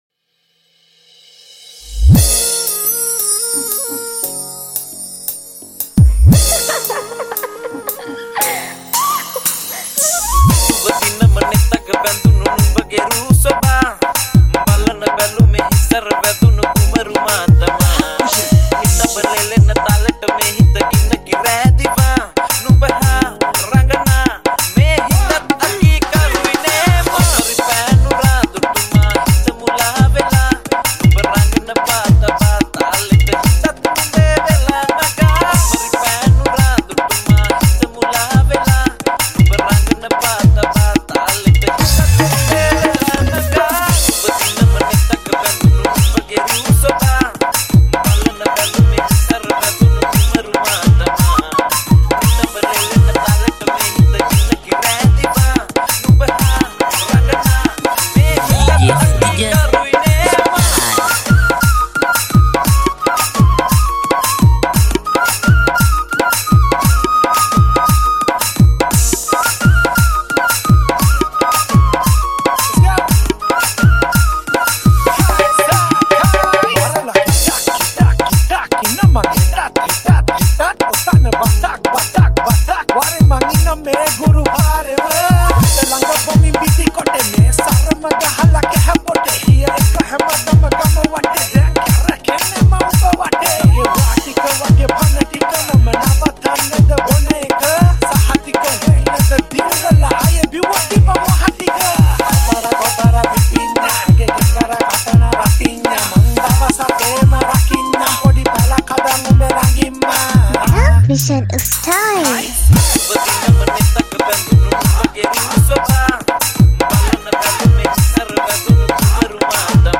High quality Sri Lankan remix MP3 (2.5).